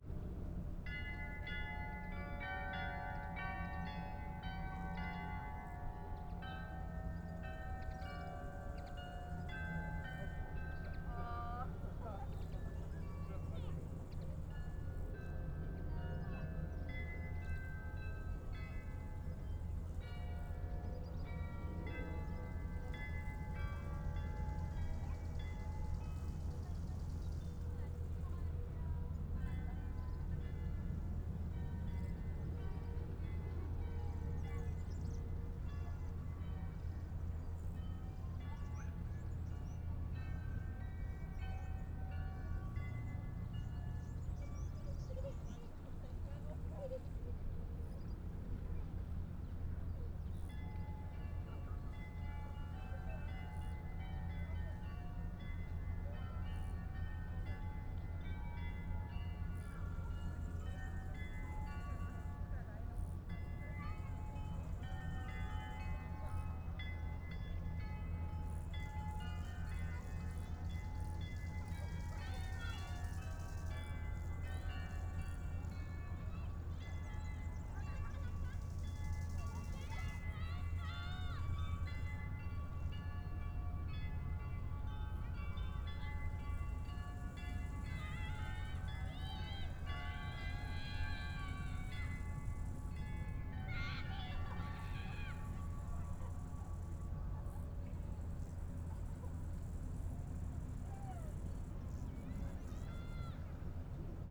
AMB TOWN Vineyard Distant Glockenspiel Walla.wav